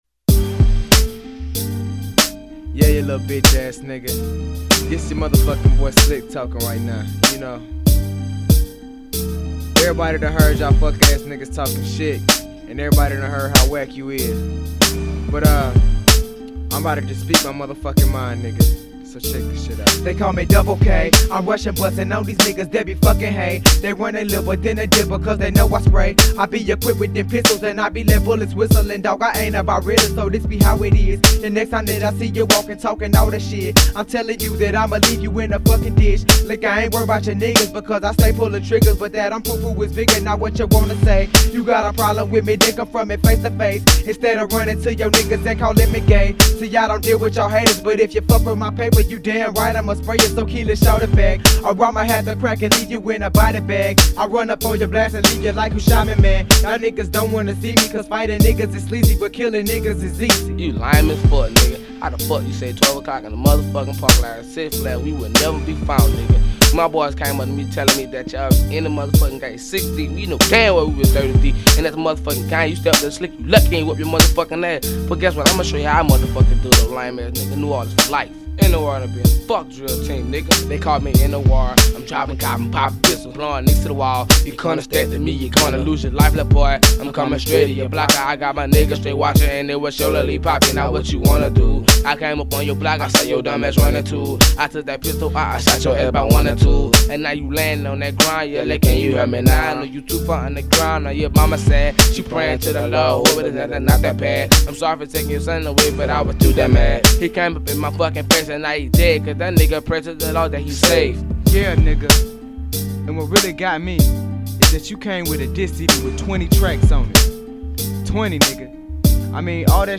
Genre: Southern Rap.